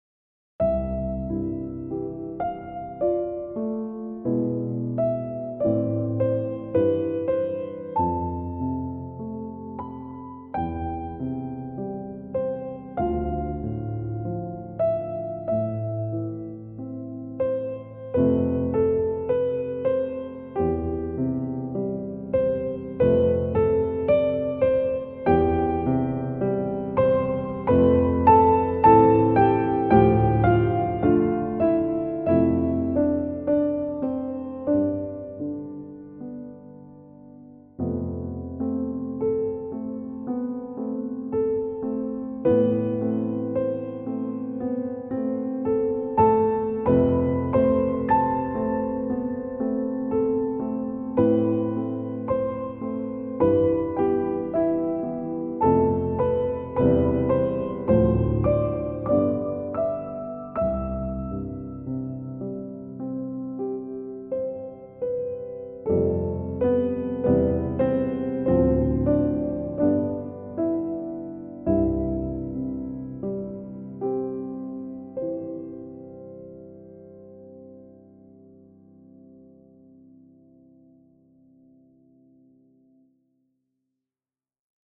Жанр: Classical